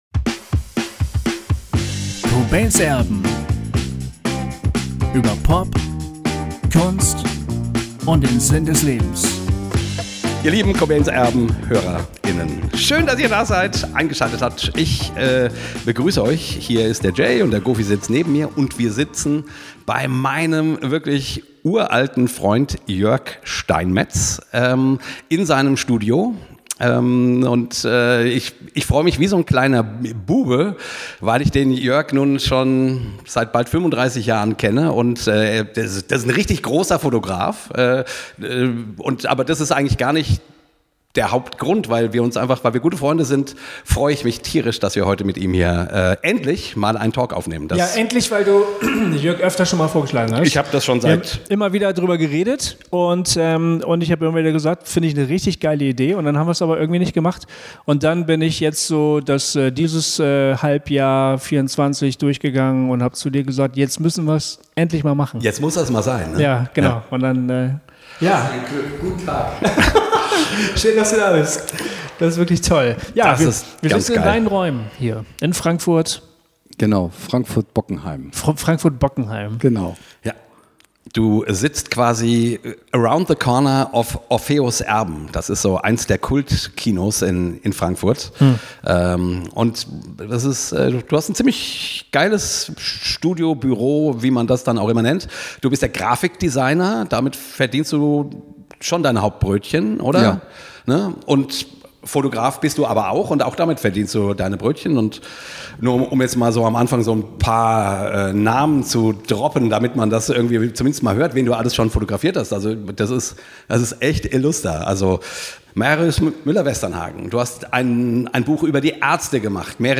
In diesem wirklich tollen Gespräch haben wir gemeinsam herausgefunden, dass der magische, der besondere Moment, der auch zu einem guten Foto führt, dann ist, wenn es zu einer echten, aufrichtigen Begegnung zwischen Menschen kommt, wenn einer den anderen wirklich sieht.